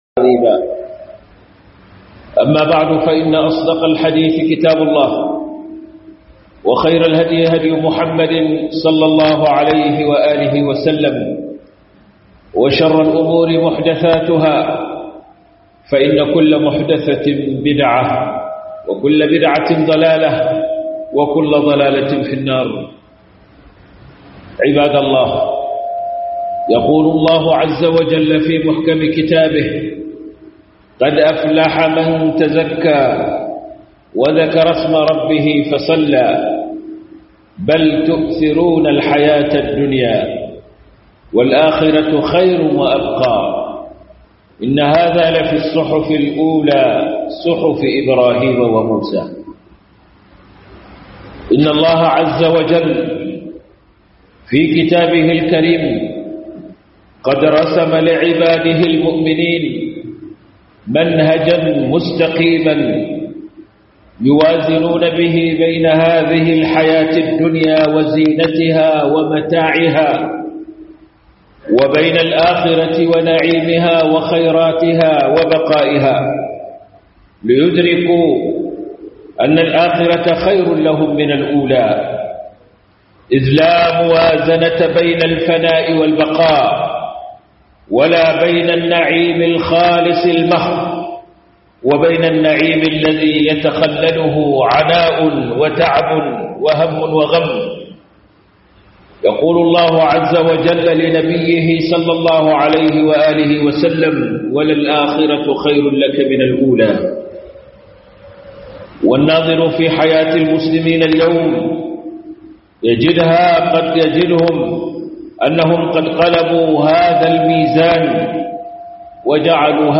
FIFITA LAHIRA AKAN DUNIYA - HUDUBA